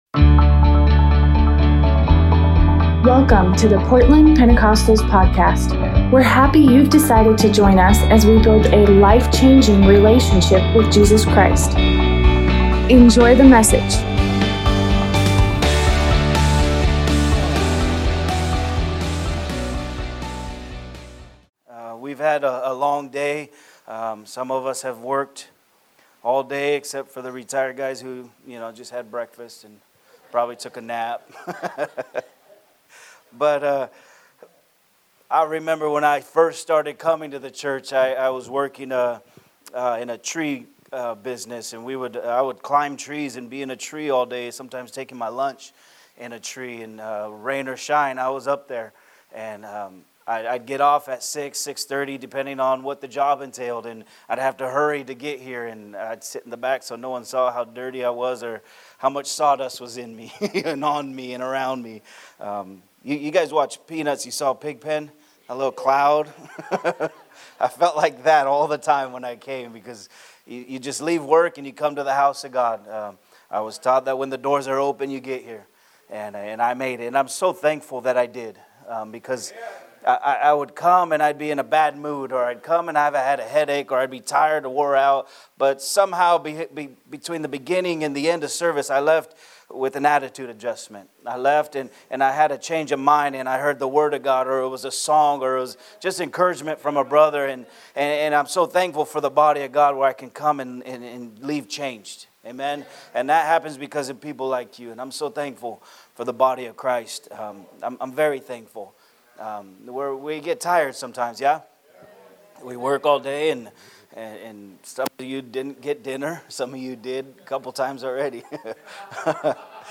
Tuesday night Bible study